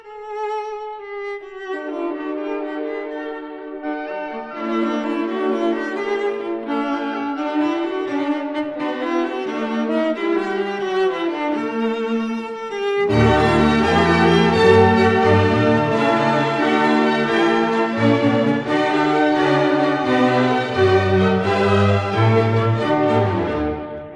A 1960 stereo recording